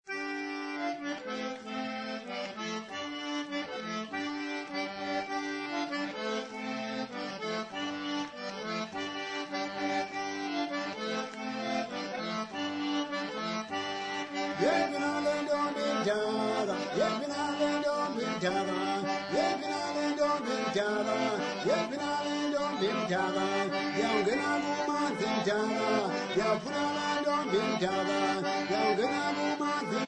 Folk music
Sacred music
Field recordings
sound recording-musical
Ngqoko Group participants at the University of Fort Hare
7.5 inch reel